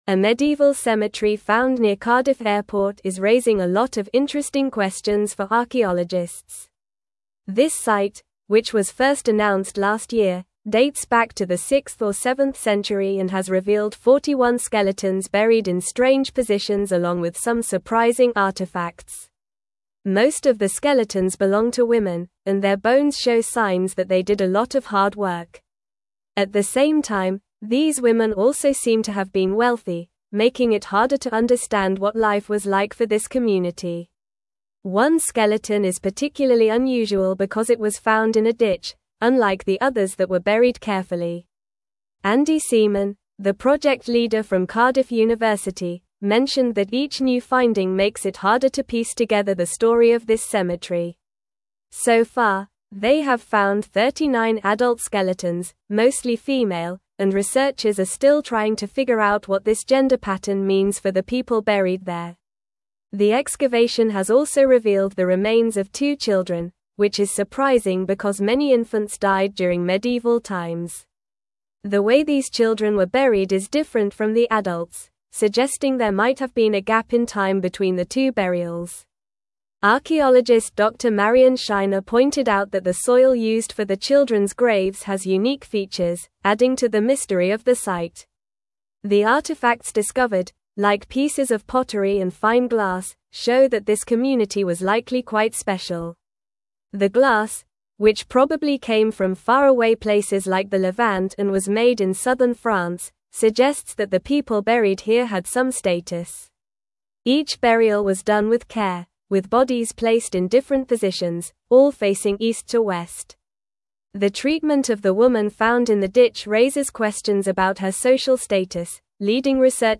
Normal
English-Newsroom-Upper-Intermediate-NORMAL-Reading-Medieval-Cemetery-Near-Cardiff-Airport-Unveils-Mysteries.mp3